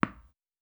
Wall Hit Normal.wav